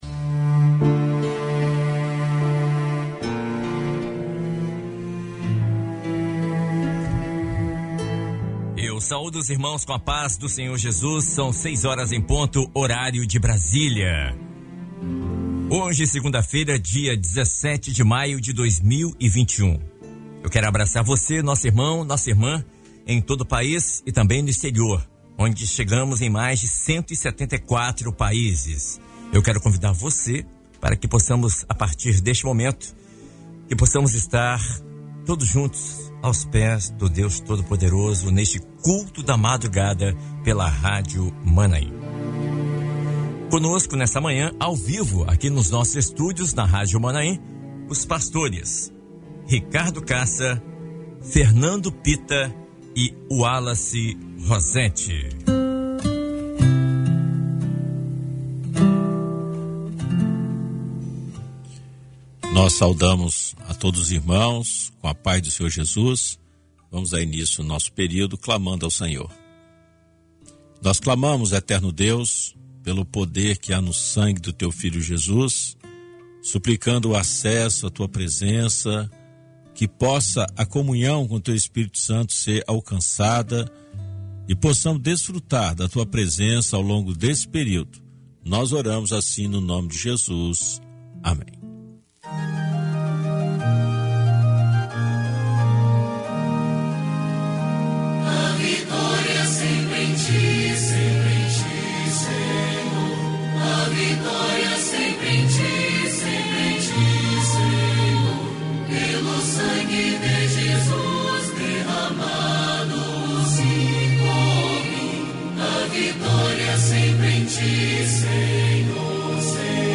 Culto de oração transmitido dentro do programa Bom Dia Maanaim